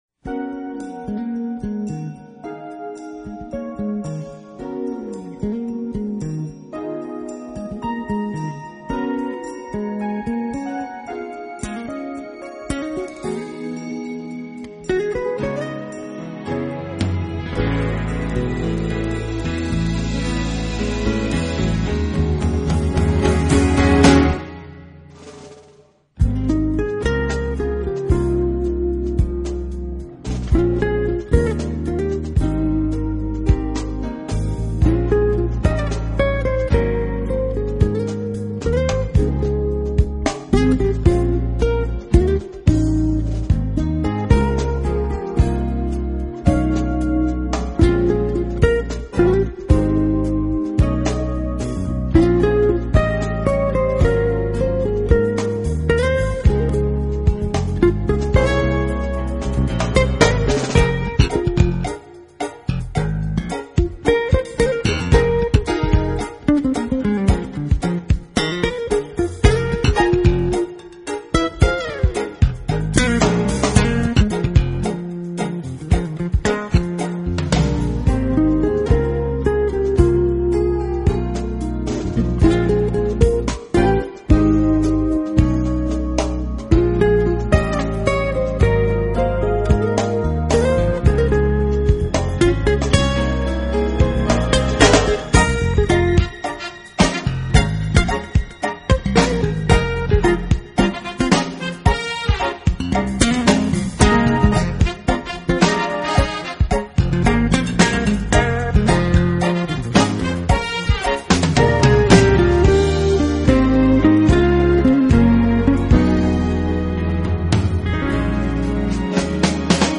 【爵士吉他】
专辑类型：Jazz